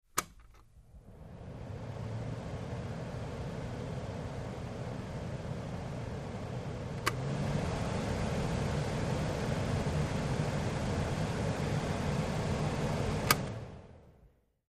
Air Conditioner 1; Air Conditioner Blows; On Switch Followed By Low Speed Air Blows, A Speed Switch, High Speed Air Blows, And Then Switched Off. Close Perspective. Fan.